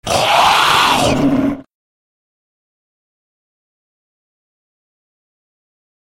Звуки мумий
Здесь собраны уникальные аудиозаписи, воссоздающие атмосферу древних гробниц: от приглушенных стонов до зловещего шелеста бинтов.